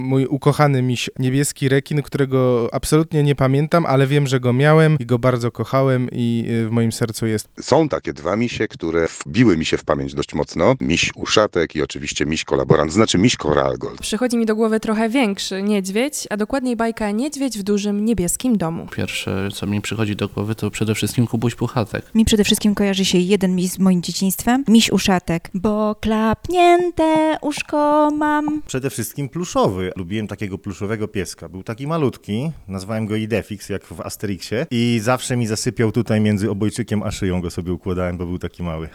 Pracownicy Radia Radom, jako duże dzieci, także powiedzieli jaki miś kojarzy im się z dzieciństwa: